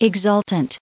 added pronounciation and merriam webster audio